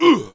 client / bin / pack / Sound / sound / monster / barbarian_boss / damage_1.wav
damage_1.wav